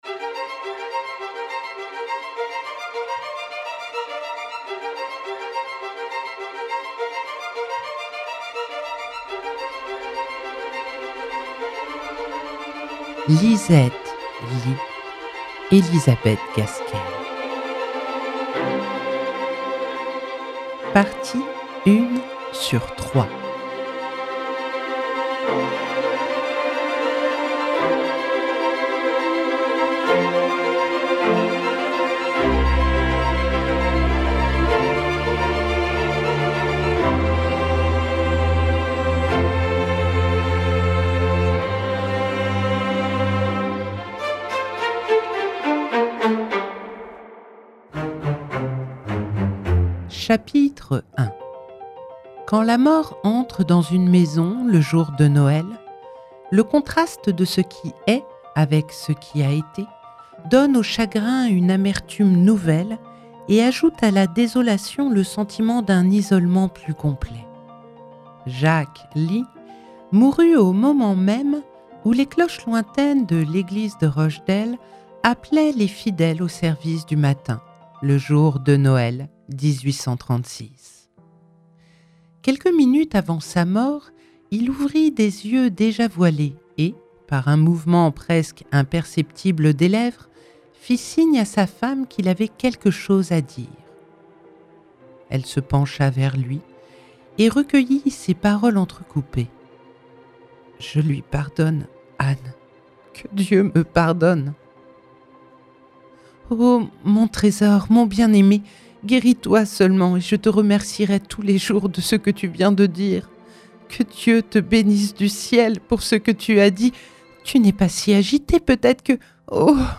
🎧 Lisette Leigh – Elizabeth Gaskell - Radiobook